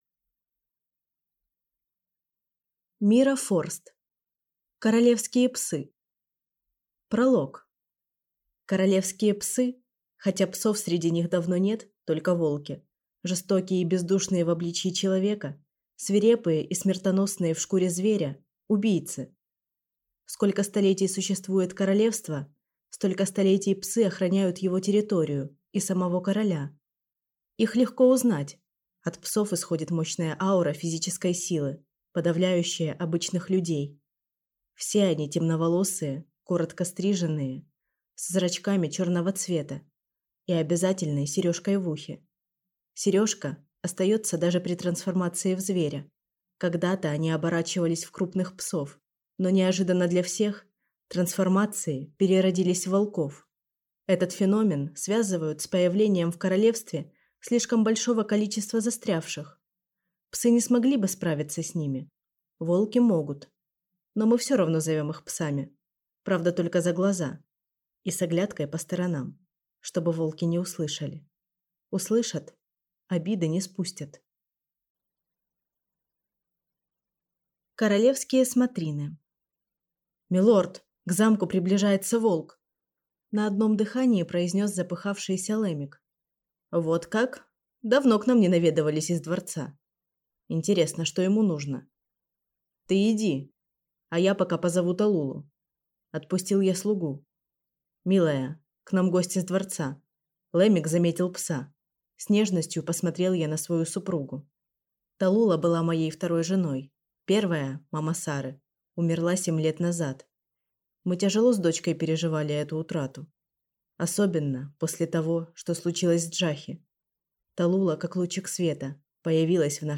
Аудиокнига Королевские псы | Библиотека аудиокниг